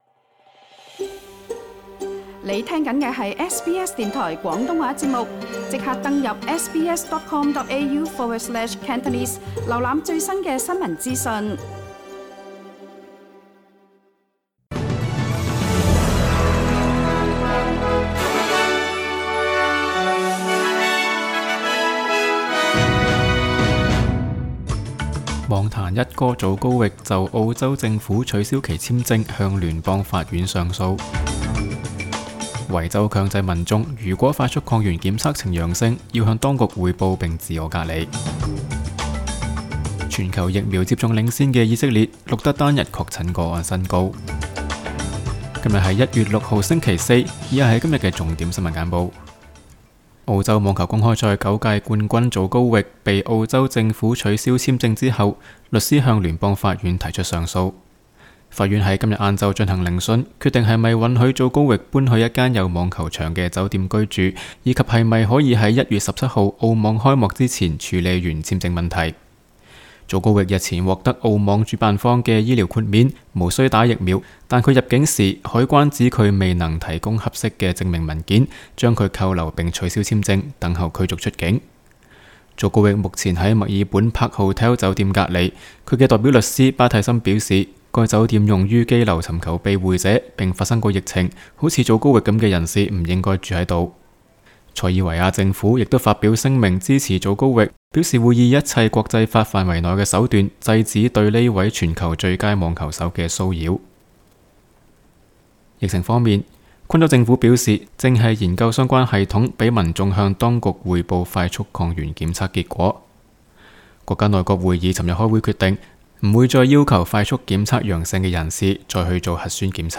SBS 新闻简报（1月6日）